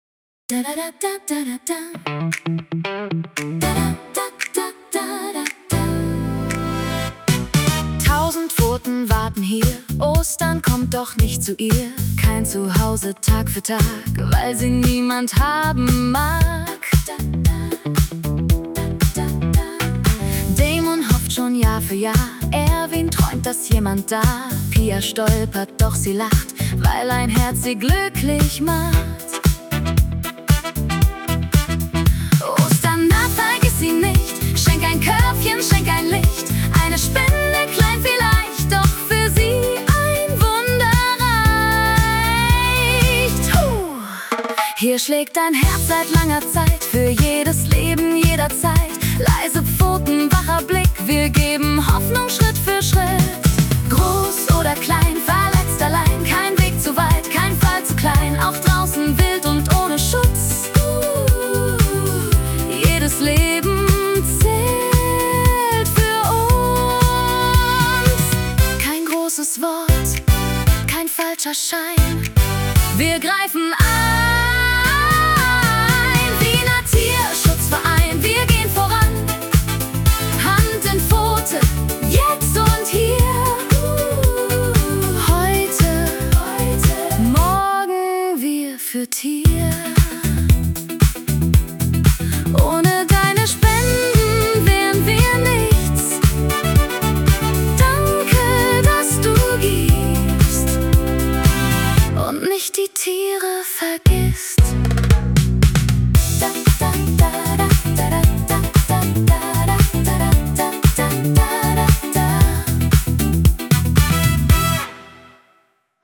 tierischen Ostersong.